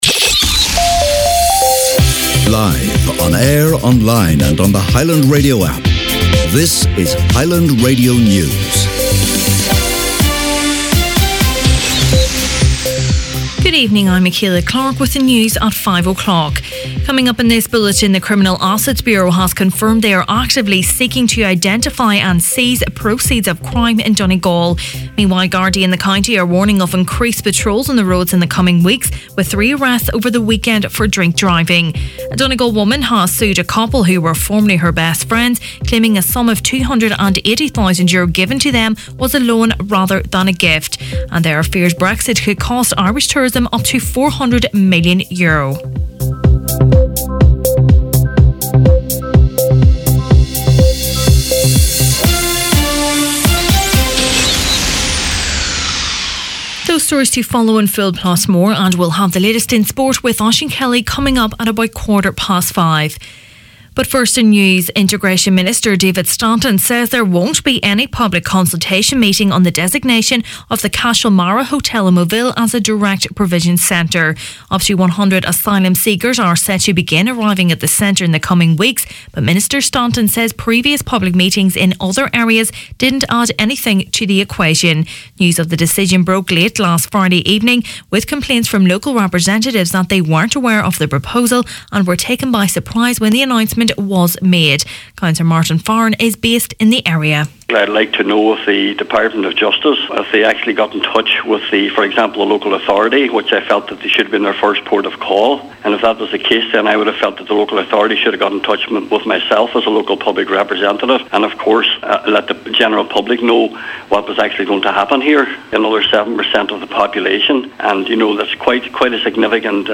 Main Evening News, Sport and Obituaries Monday November 12th